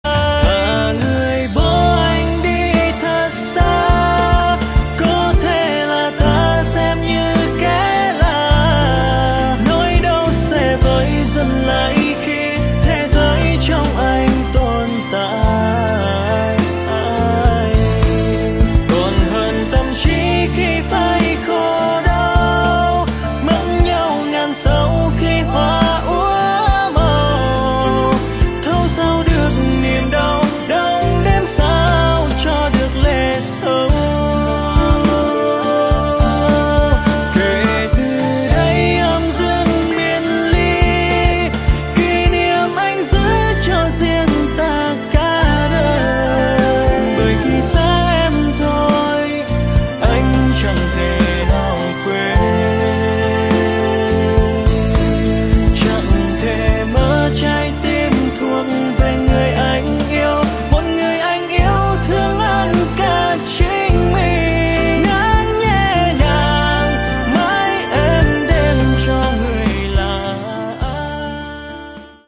Nhạc chuông điện thoại